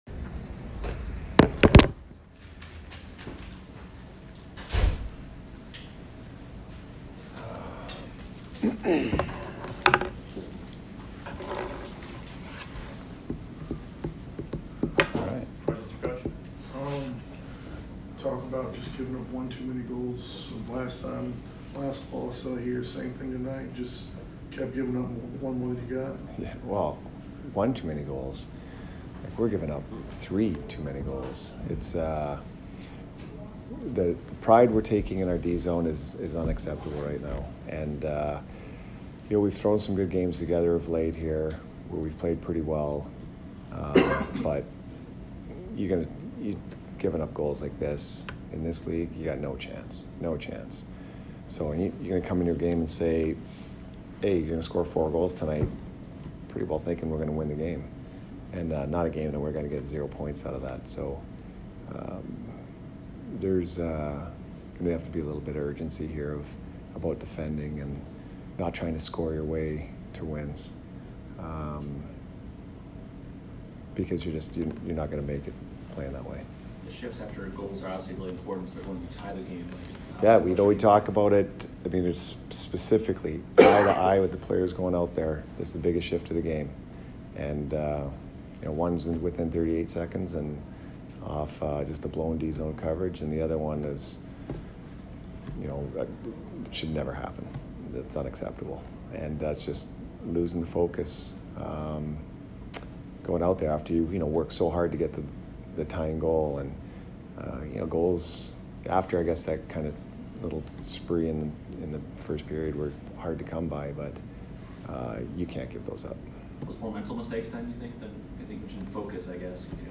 Cooper post-game 12/5